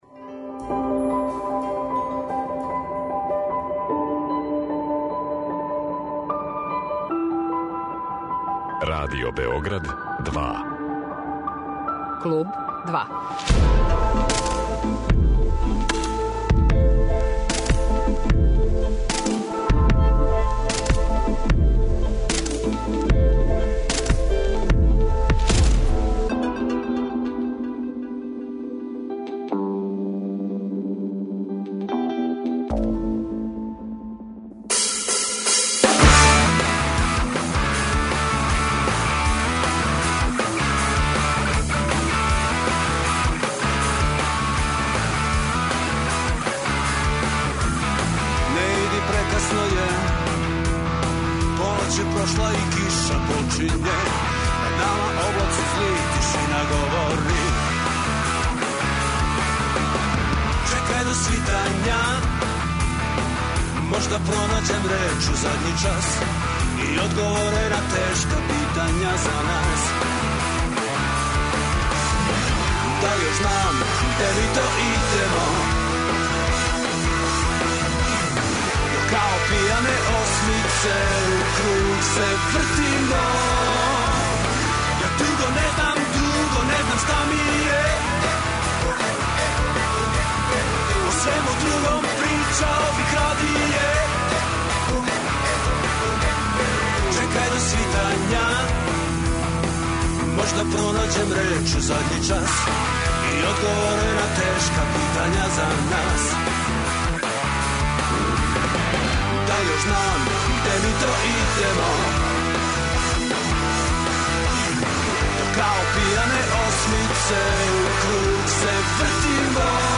Гост емисије Клуб 2, биће Никола Чутурило Чутура, музичар.
О овом мјузиклу и својој, широј јавности познатијој, рокерској каријери као и намерама и сврси овог издања у лаганом послеподневном разговору на таласима Радио Београда 2.